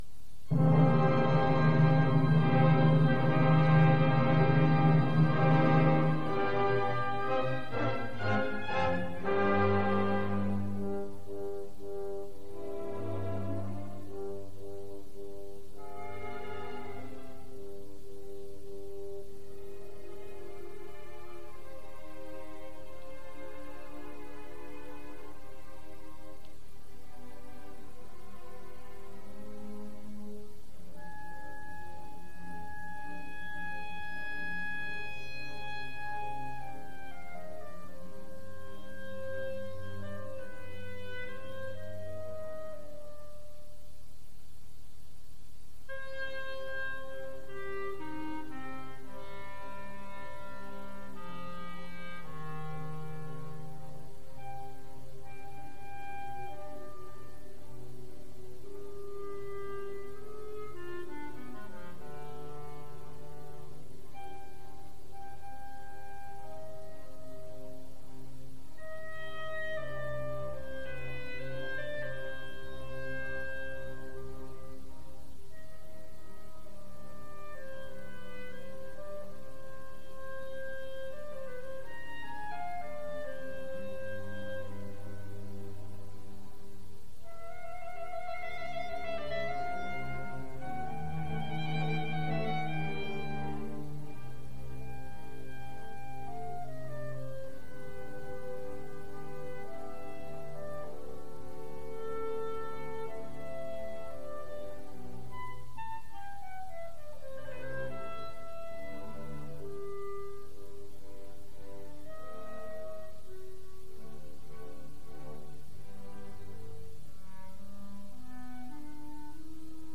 Clarinet and Ensemble  (View more Advanced Clarinet and Ensemble Music)
Classical (View more Classical Clarinet and Ensemble Music)
Audio: Urbana - Prairie Performances, WILL-FM
clarinet-concertino-j-109.mp3